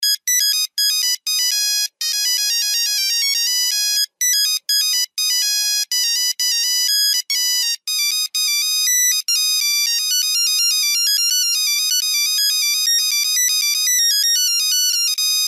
Рингтоны нокиа , Рингтоны старый телефон